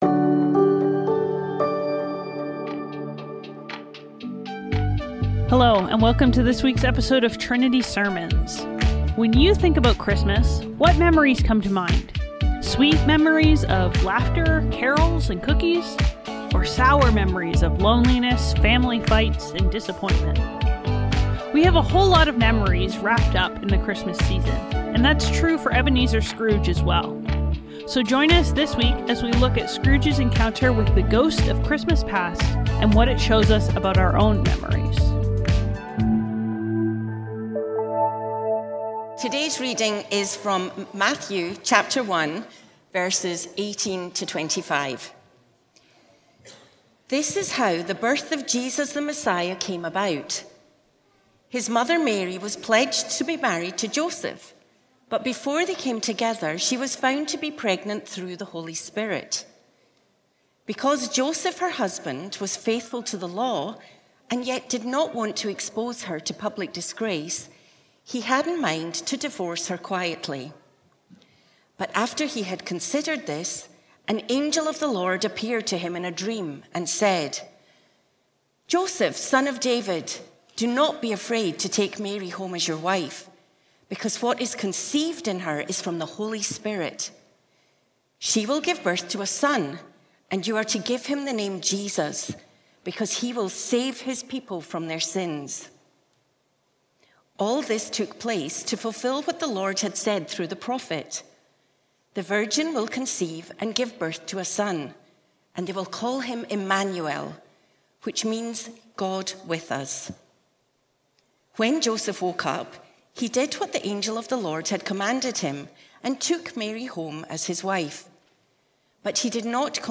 Trinity Streetsville - Haunted | The Ghosts of Christmas | Trinity Sermons